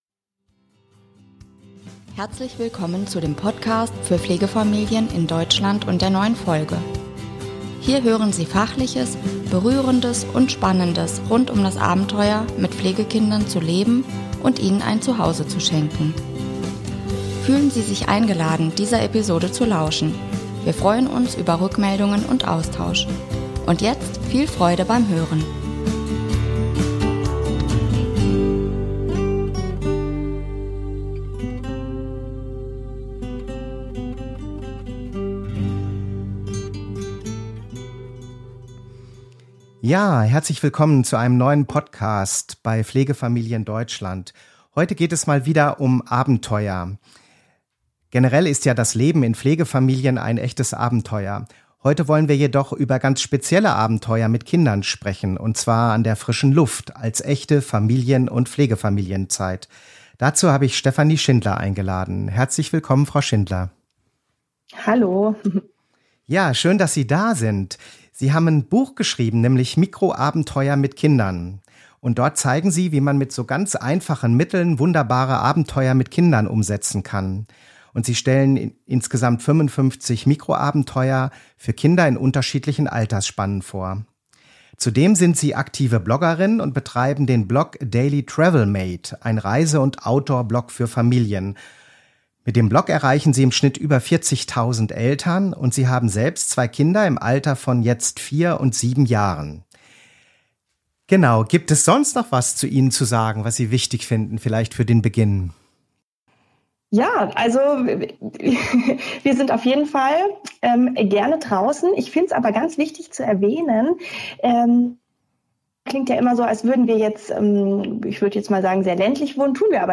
Fragen für das Interview: